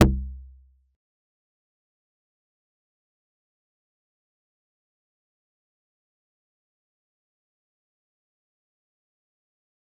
G_Kalimba-F1-pp.wav